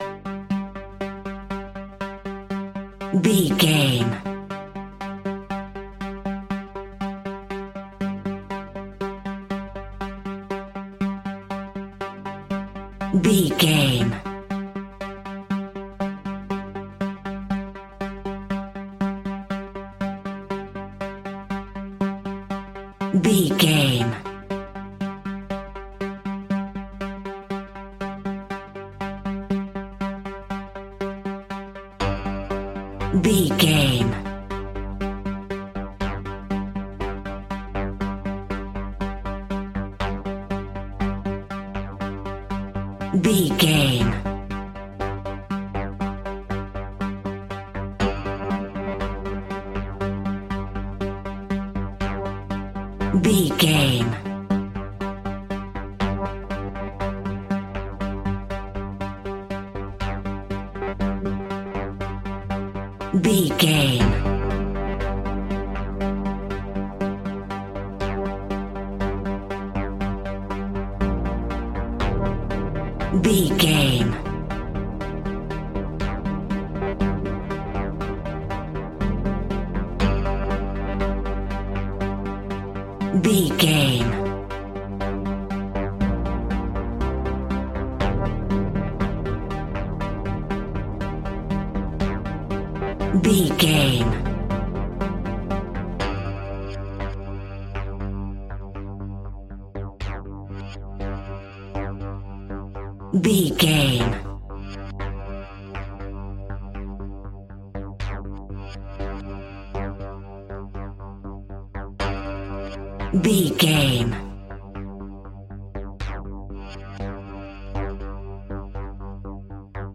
Aeolian/Minor
ominous
dark
haunting
eerie
synthesiser
drum machine
mysterious
horror music